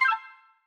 confirm_style_4_004.wav